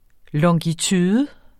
Udtale [ lʌŋgiˈtyːðə ]